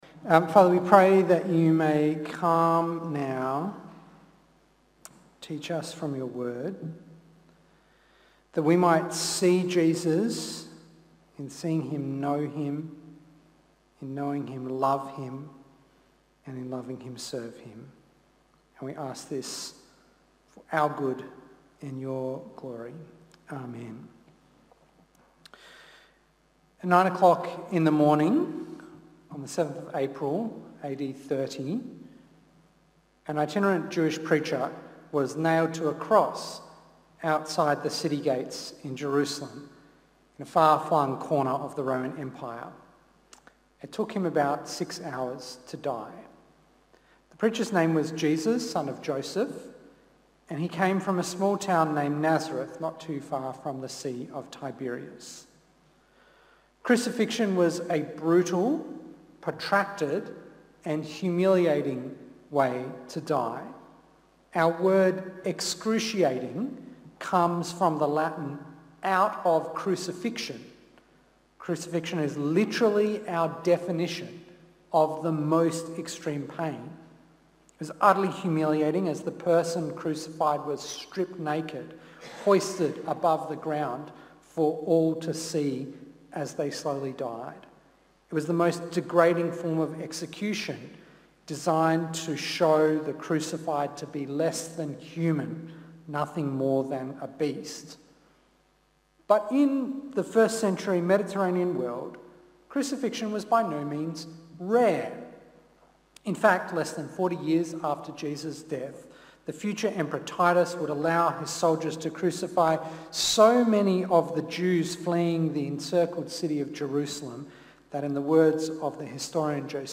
In this sermon series we're looking at the meaning and accomplishment of the cross.